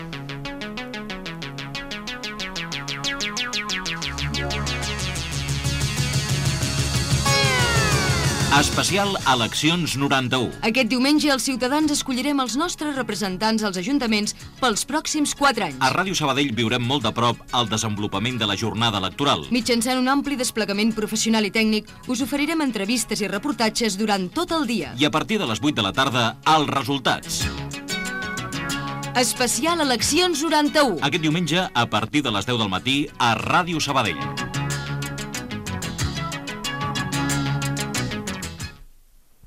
Promoció de l'especial informatiu amb motiu de les eleccions municipals 1991
Informatiu